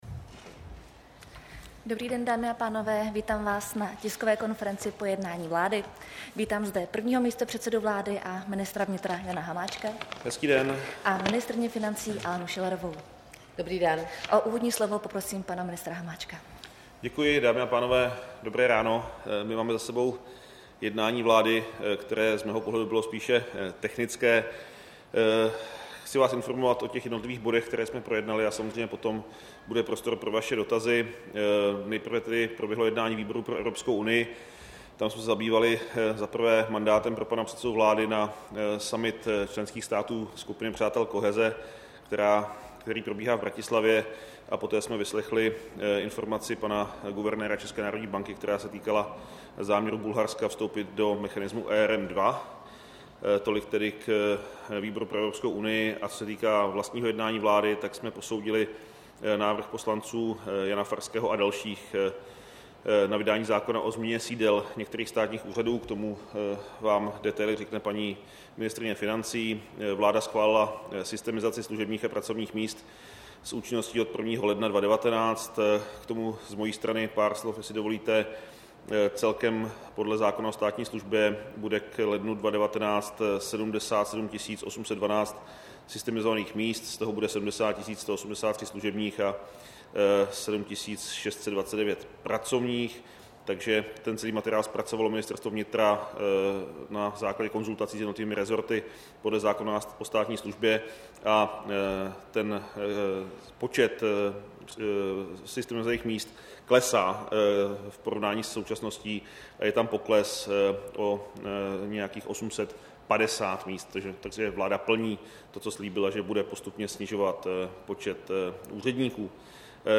Tisková konference po jednání vlády, 29. listopadu 2018